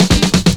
DRUMFILL04-R.wav